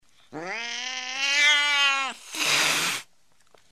Звуки злого кота
На этой странице собраны звуки злого кота: агрессивное мяуканье, шипение, рычание и другие проявления кошачьего недовольства.
Кот злобно мяукнул и яростно зашипел